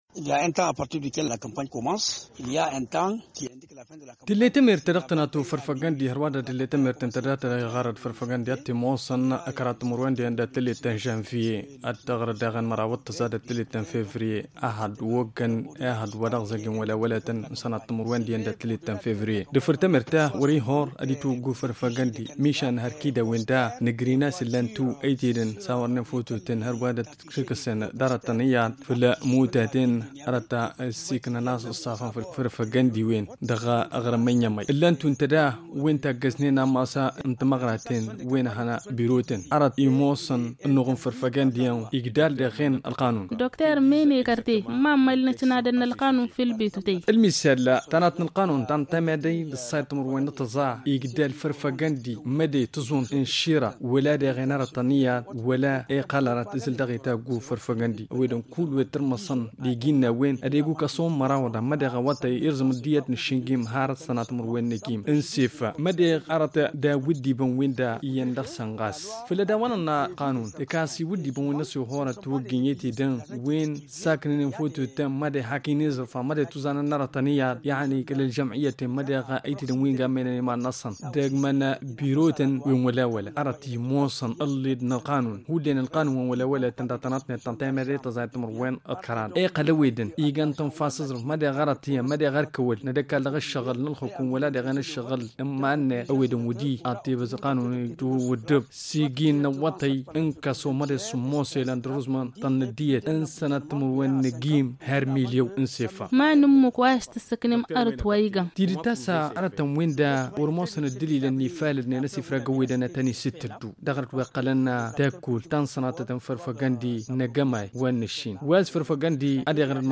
joint au téléphone